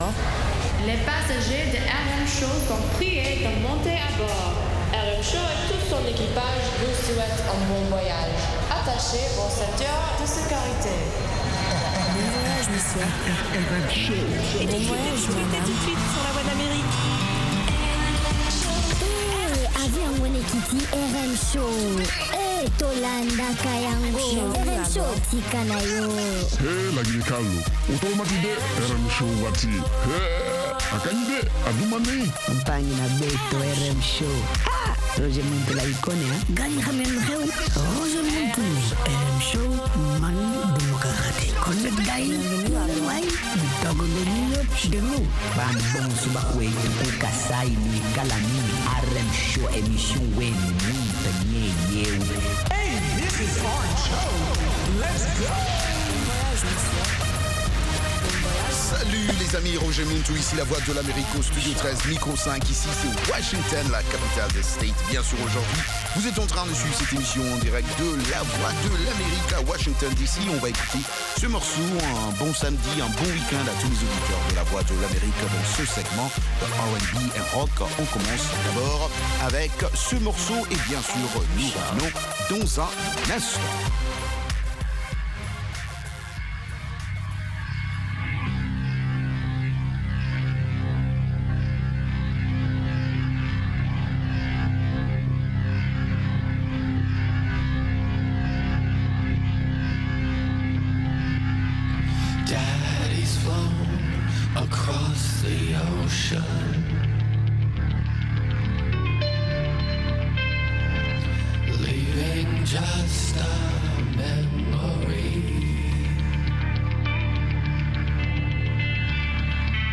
RM Show - R&B et Rock
- Les orchestres gigantesques et les groups qui ont grandement contribué a changer les mœurs et société, ce classement comprend divers genre musicaux (Rap, Rock, Pop, R&b etc.) afin de satisfaire le plus grand nombre.